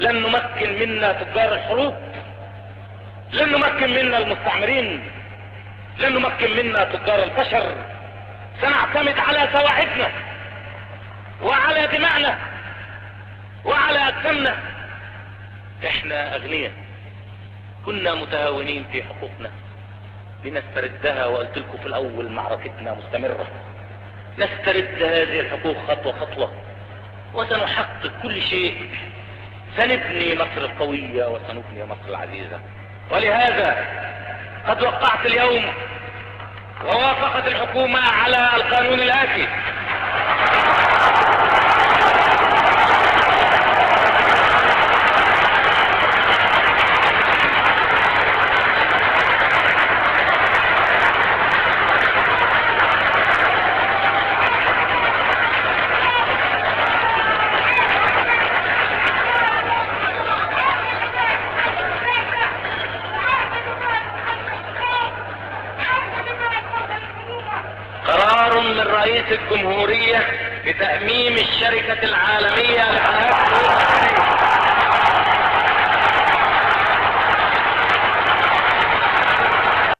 Playing in Background - 1954 Nasser speech - you can also download it from below